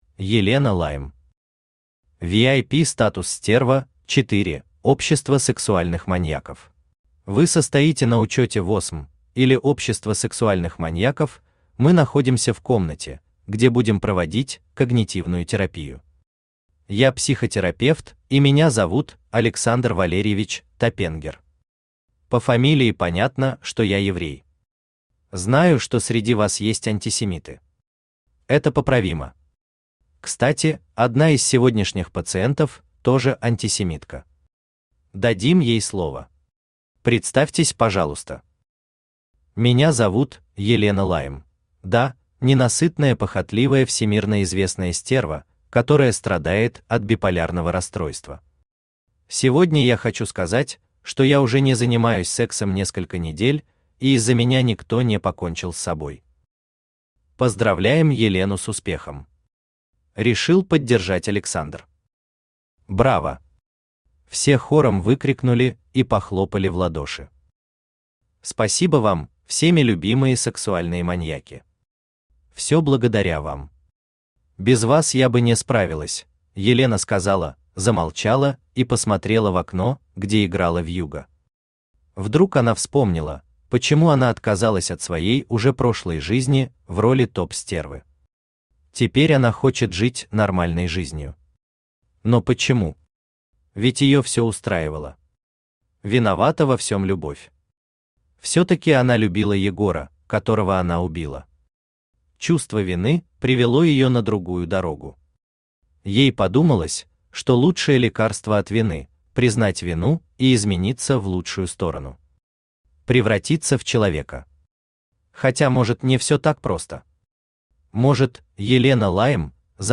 Aудиокнига VIP-статус «Стерва» – 4: Общество сексуальных маньяков Автор Елена Лайм Читает аудиокнигу Авточтец ЛитРес.